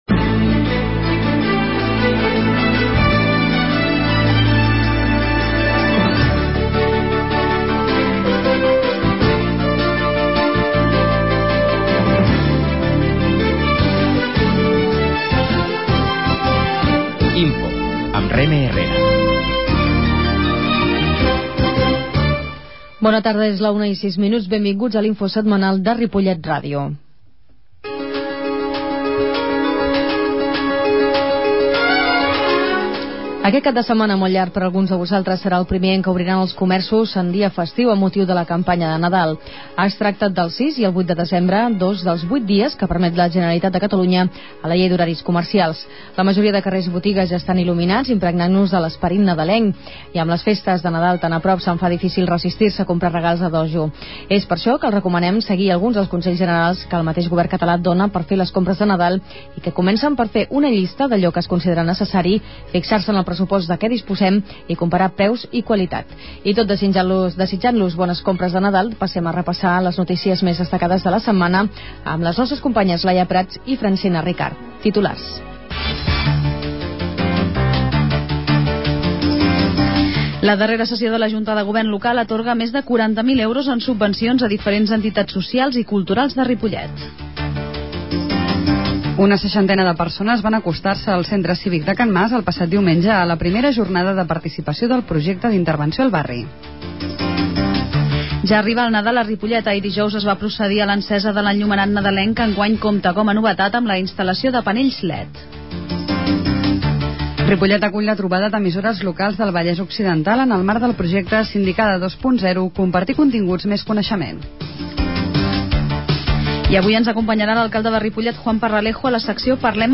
Comunicació L'INFO de la setmana: 4 de desembre de 2009 -Comunicació- 03/12/2009 Avui, a l'INFO de Ripollet R�dio hem tornat a comptar amb la participaci� de l'alcalde de Ripollet, Juan Parralejo, amb qui hem repassat les not�cies m�s destacades de l'actualitat local.
La qualitat de so ha estat redu�da per tal d'agilitzar la seva desc�rrega.